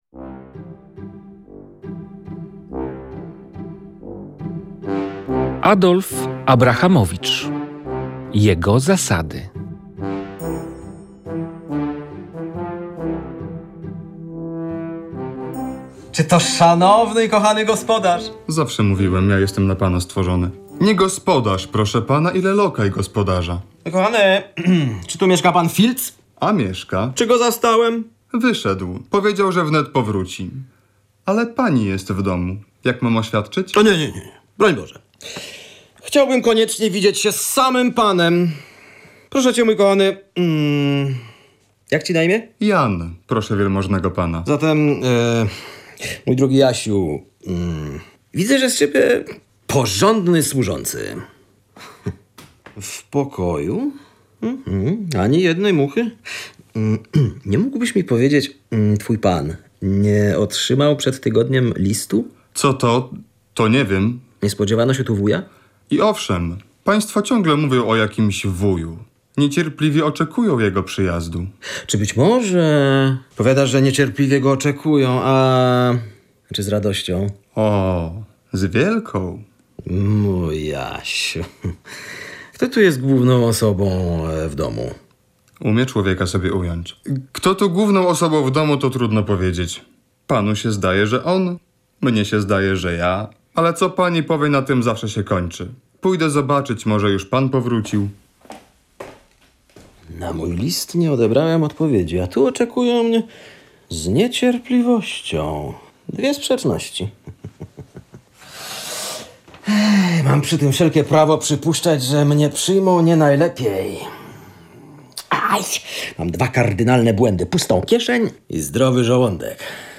słuchowisko